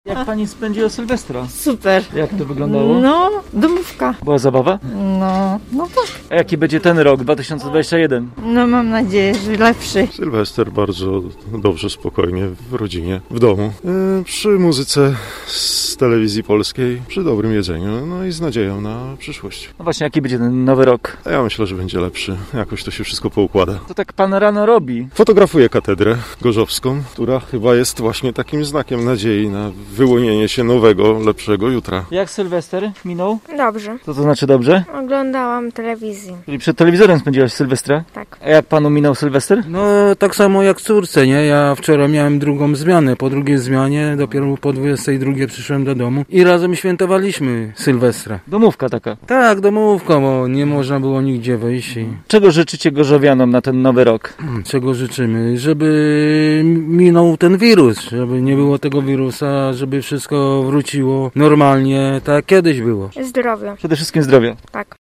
Jak nasi mieszkańcy spędzili kilkanaście godzin starego i nowego 2021 roku – pytaliśmy dzisiaj na ulicach miasta.